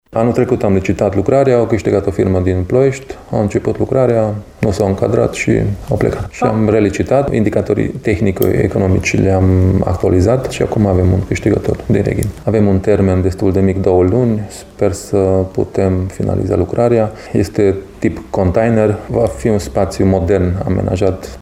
Proiectul s-a reluat în această vară și ar trebui să fie gata până la toamnă, spune viceprimarul din Reghin Mark Endre: